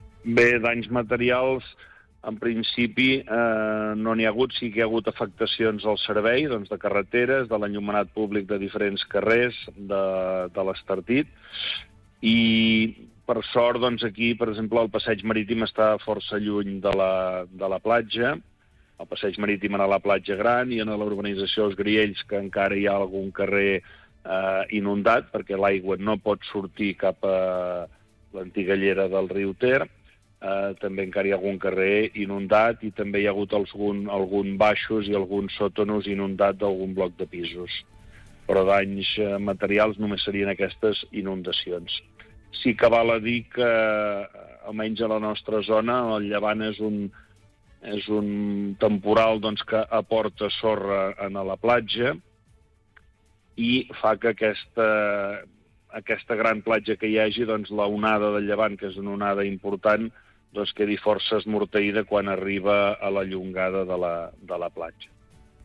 El president de l’EMD de l’Estartit, Genís Dalmau, ha explicat durant el programa del Supermatí d’aquest dimecres que les principals afectacions que va patir l’Estartit durant el temporal van ser la inundació de diversos carrers, tot i que ja són conscients dels efectes que tenen les llevantades a l’Estartit.